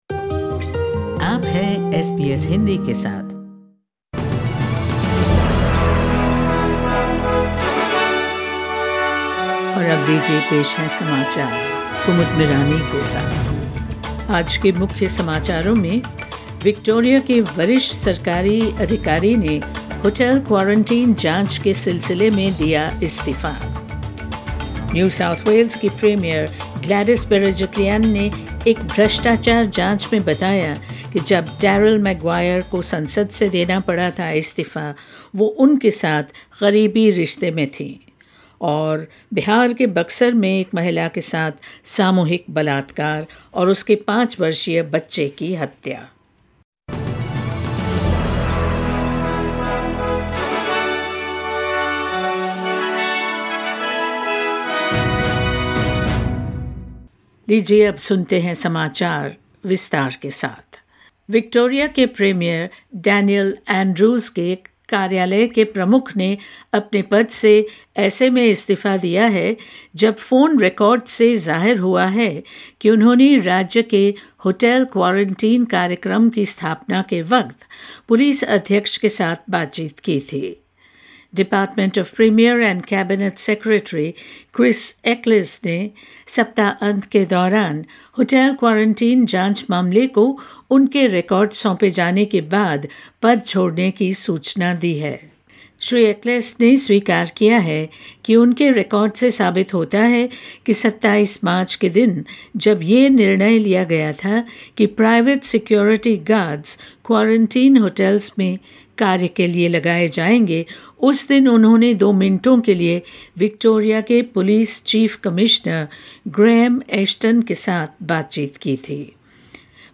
News in Hindi 12 October 2020